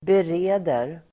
Uttal: [ber'e:der]